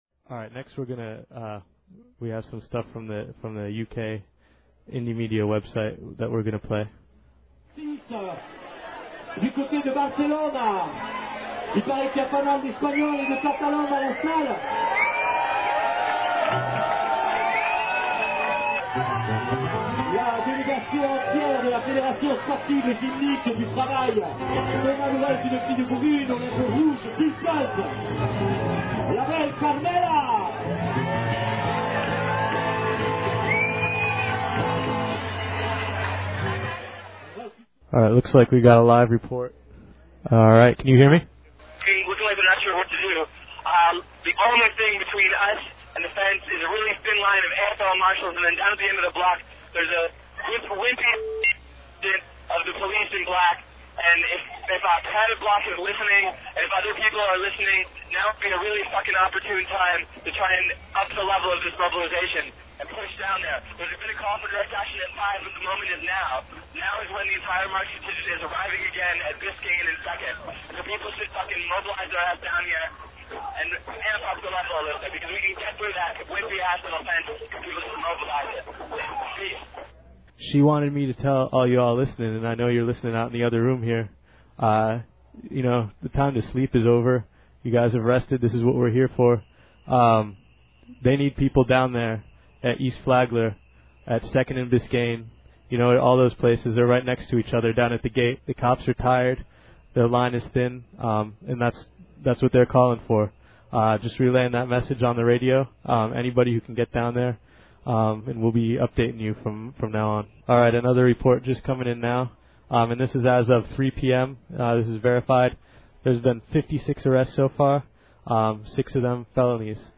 Here is audio spanning about 5 hours that I edited down to just the call-ins.
Live Call-Ins From Resistance Radio At FTAA Miami 20nov
Here are many of the radio call-ins people were giving live while the teargas and dumdum bullets were flying. This one's low-fi.
callins20nov03_lofi.mp3